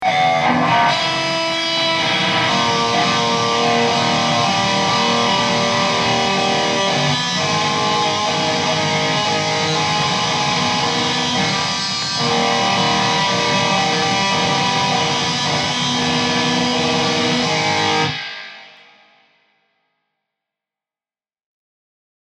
Die Gitarre reagiert in Garageband mega empfindlich, muss ich sagen. Beim Livespiel hört man überwiegend nur ein Rauschen, aber die Aufnahme ist klar. jedoch ist der Sound immer noch nicht reif für Metalcore.